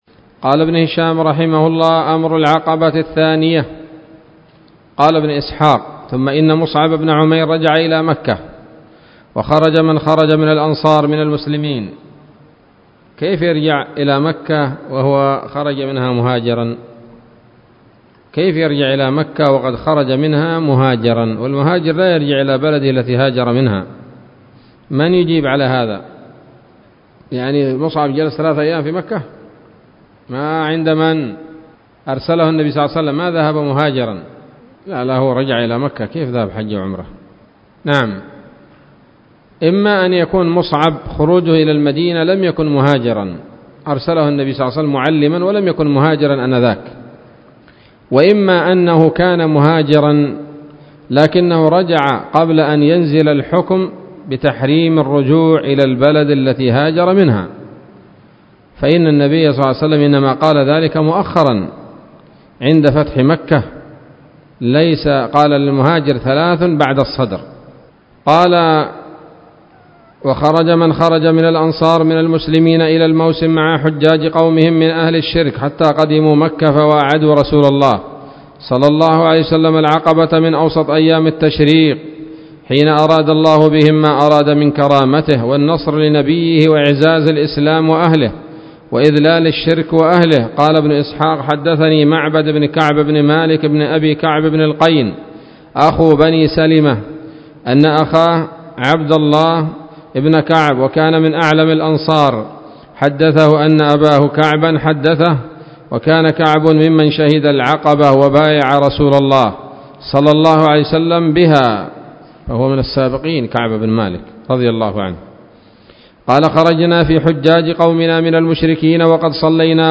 الدرس الثالث والستون من التعليق على كتاب السيرة النبوية لابن هشام